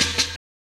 Closed Hats
HIHAT BREAK 2.wav